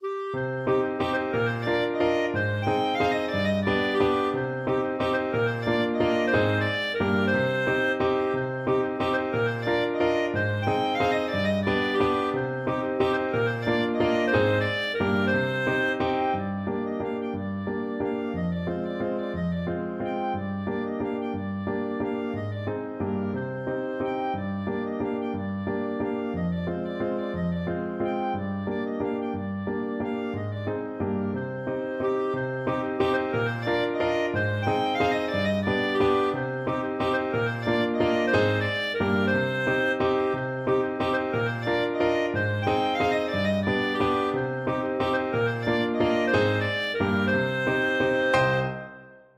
Clarinet
3/4 (View more 3/4 Music)
One in a bar . = c. 60
C minor (Sounding Pitch) D minor (Clarinet in Bb) (View more C minor Music for Clarinet )
Easy Level: Recommended for Beginners with some playing experience
Traditional (View more Traditional Clarinet Music)